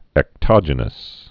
(ĕk-tŏjə-nəs)